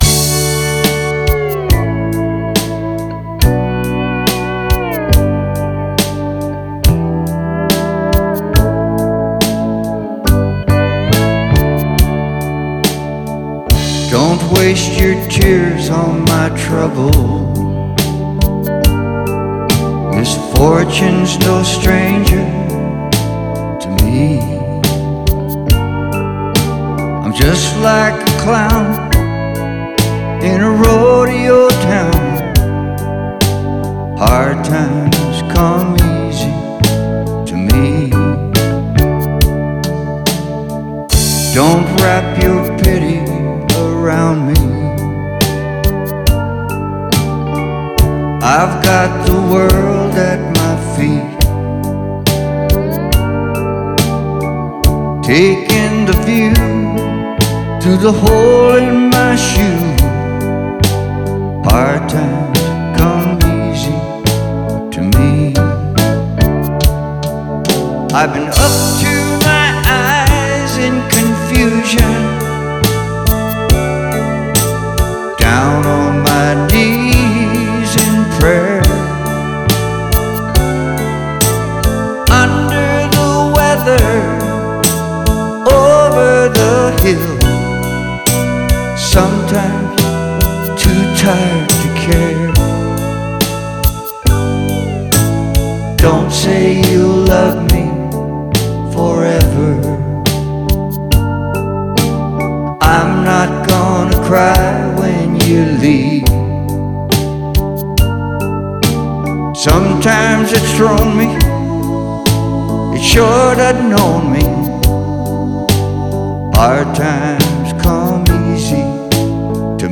vocals, keyboards
MSA pedal steel